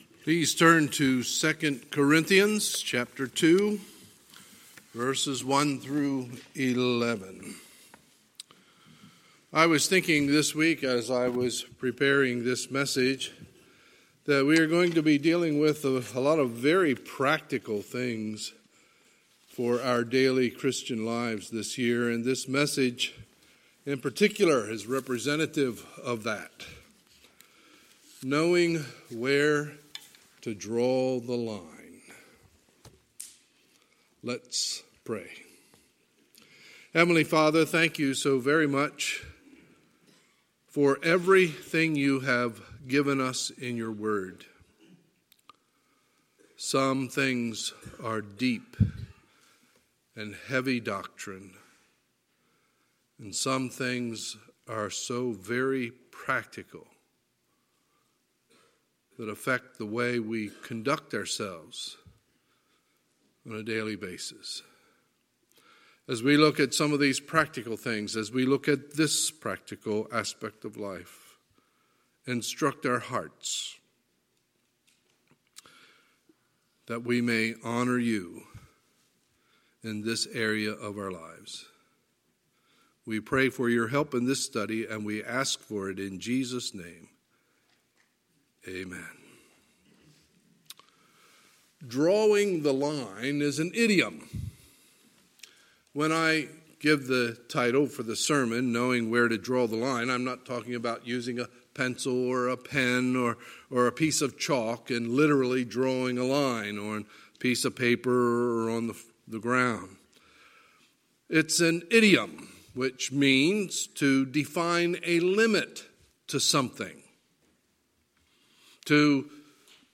Sunday, February 2, 2020 – Sunday Morning Service